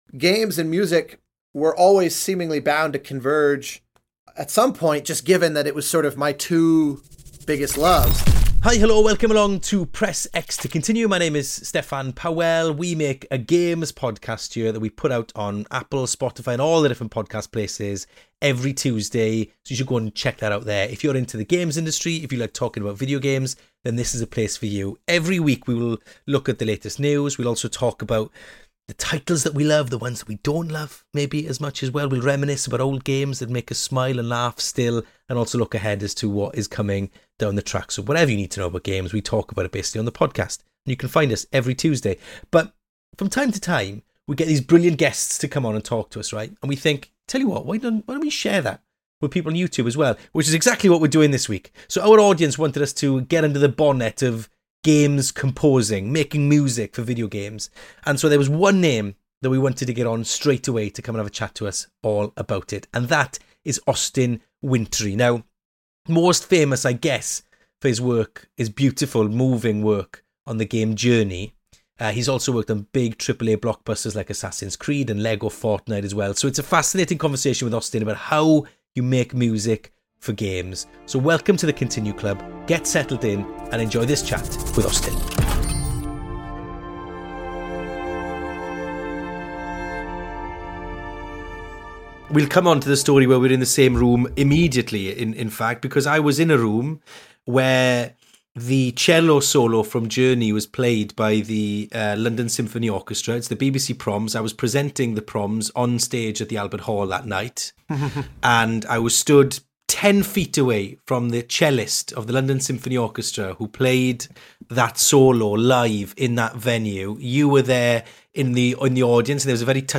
a long old pan-Atlantic chat about everything aural with seasoned video game composer Austin Wintory